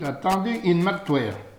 Langue Maraîchin
Patois - ambiance
Catégorie Locution